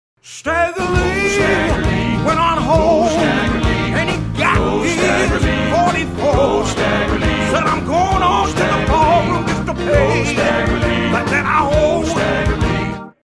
Enjoy the great sounds of Motown, Soul, R&B and Rock